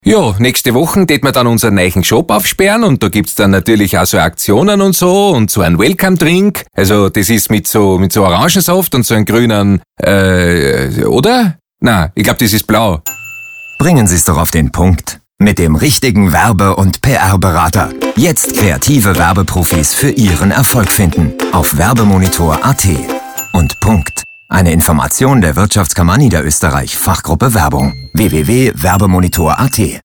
Die Expertentipps werden jeweils von niederösterreichischen Firmeninhabern gesprochen, um das kreative Potential für potentielle Auftragnehmer aufzuzeigen.